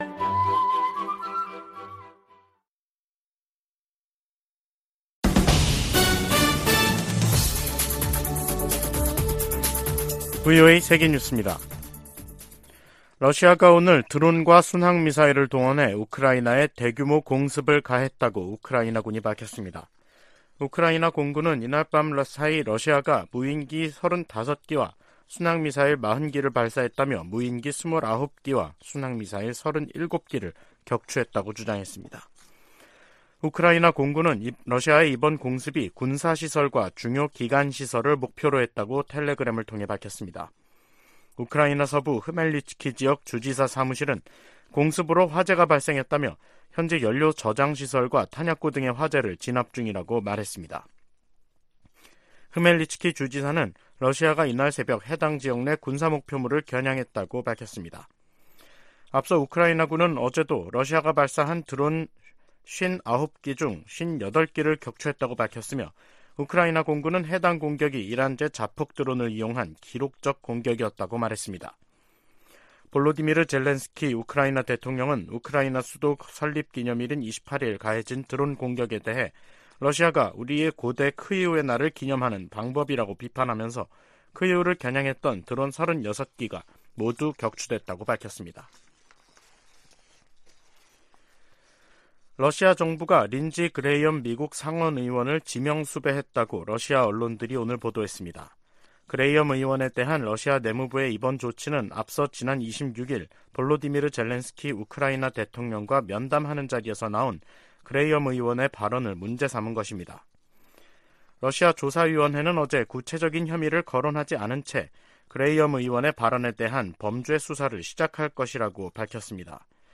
VOA 한국어 간판 뉴스 프로그램 '뉴스 투데이', 2023년 5월 29일 2부 방송입니다. 북한이 군사정찰위성 1호기 발사 계획을 공개했습니다. 한국 정부는 발사를 철회하지 않으면 대가를 치를 것이라고 경고했습니다.